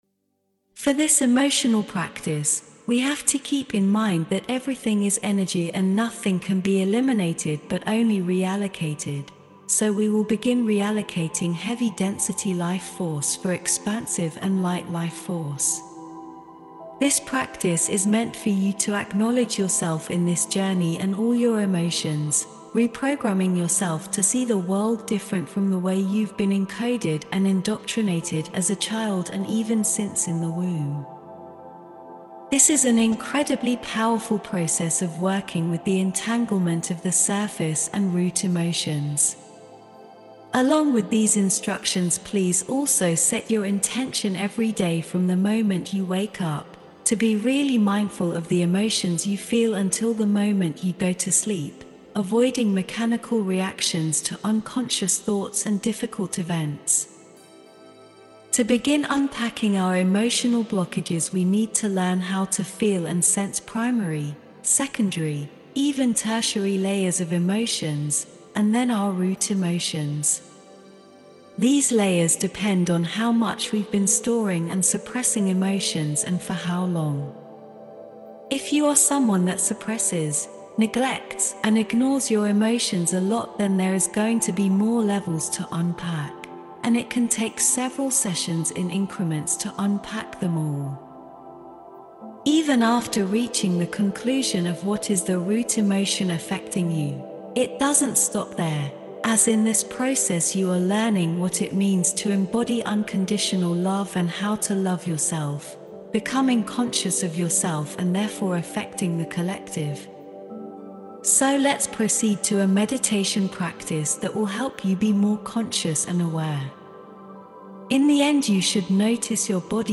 Unlocking Emotional Freedom: A Guided Meditation for Subconscious Emotional Reprogramming
Meditation-energytuneup-2.mp3